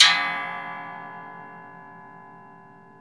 METAL HIT 1.wav